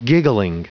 Prononciation du mot giggling en anglais (fichier audio)
Prononciation du mot : giggling